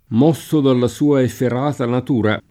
m0SSo dalla S2a effer#ta nat2ra] (Machiavelli); la empietà efferatissima contro alle donne [